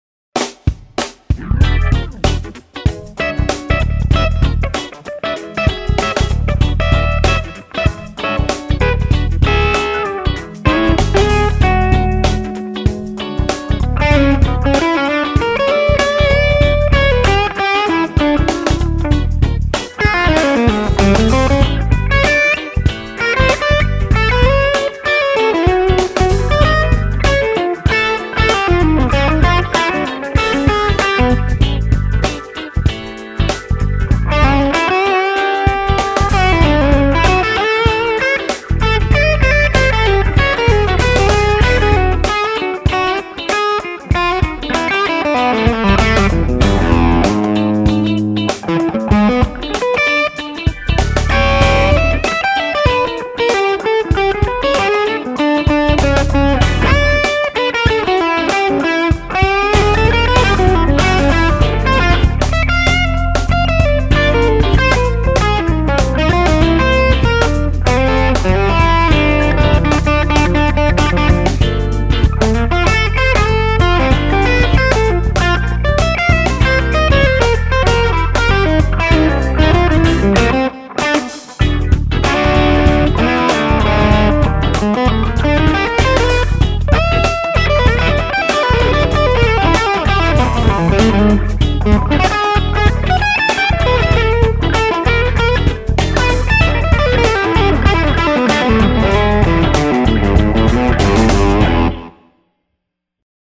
(mids trimmer at 10K, 1uf on V2b, RFT tube in V2)